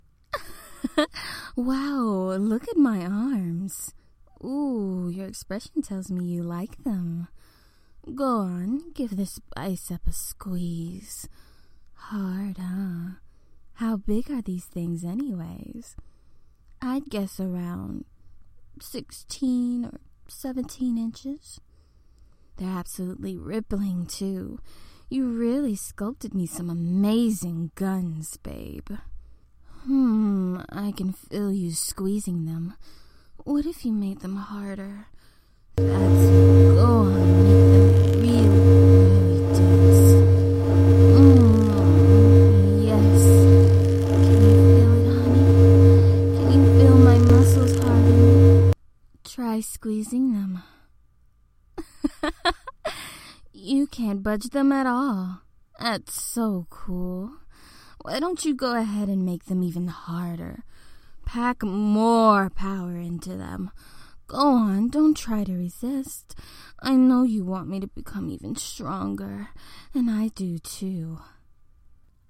Muscle Talk – With Effects Bass Level 1
Muscle-Sample-Effects-Bass-1.mp3